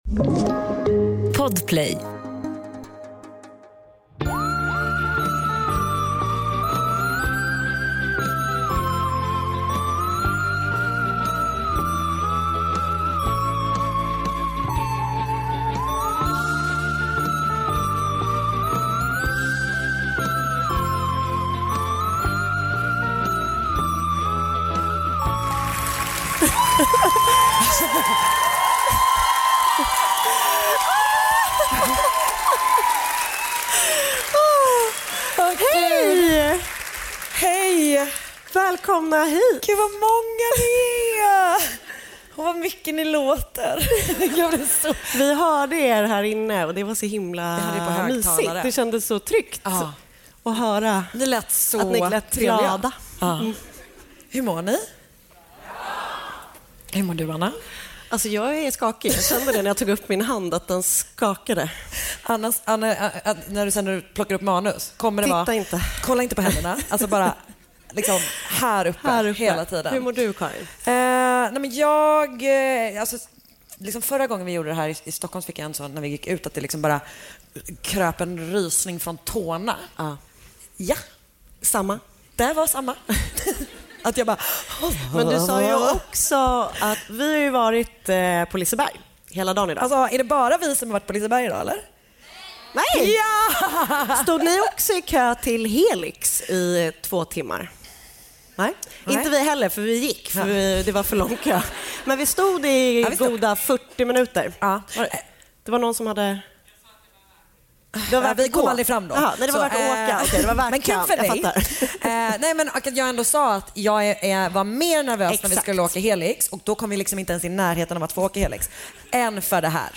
Så var det äntligen dags för livepodden på Lisebergsteatern i Göteborg!
Mord Mot Mord är en vanlig snackig podd, fast om mord. Det är lättsamt prat i ett försök att hantera världens värsta ämne.